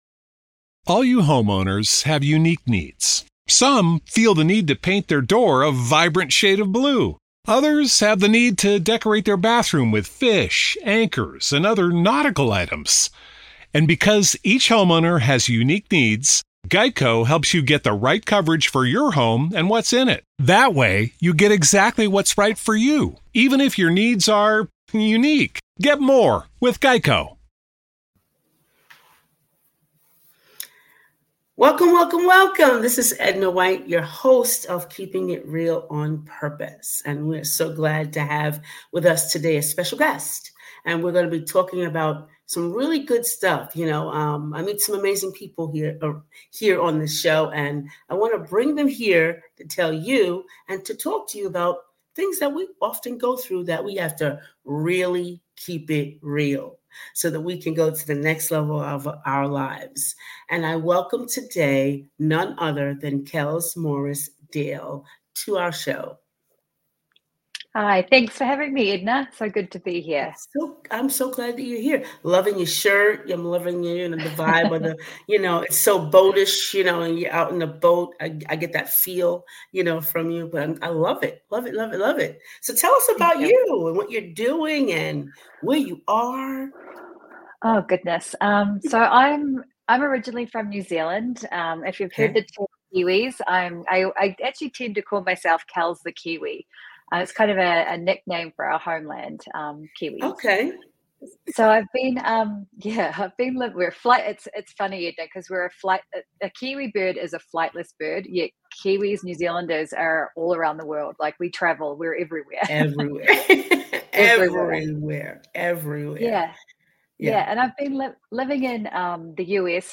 Join us for a fascinating conversation that will help you unlock your full potential and build a powerful personal brand.